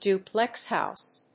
アクセントdúplex hóuse
音節dùplex hóuse